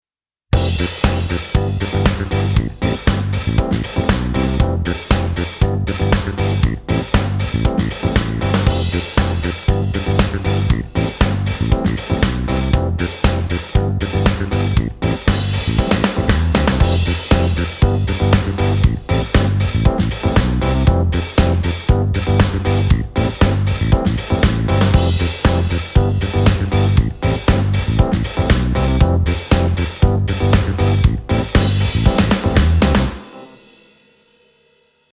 最初の8小節がMAGNETICを使わないMix、後半の8小節（0:17〜）がMAGNETICをそれぞれの楽器にインサートしたMixです。
エフェクトの使用不使用で音圧・音量に違いが出ないよう、できるだけ調整をしてあります。
MAGNETICを使用した部分の方が、音の重心が下がり、音質的にちょっと昔っぽい雰囲気になっているのが分かると思います。